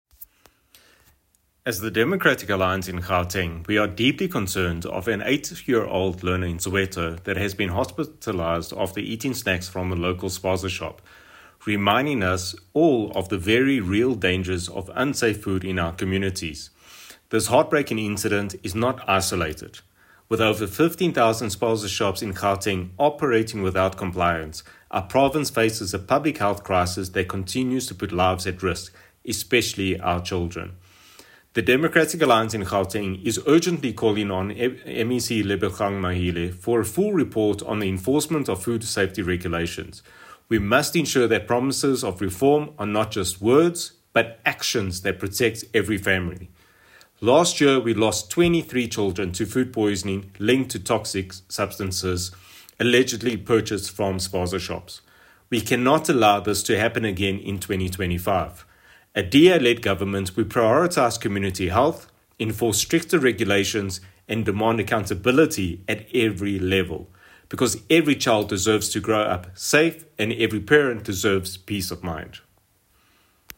Note to Editors: Please find a soundbite from DA MPL, Sergio Isa Dos Santos